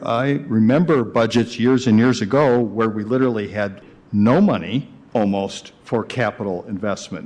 Mayor Dave Anderson says it’s a lot bigger than it has ever been.